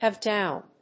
アクセントhàve dówn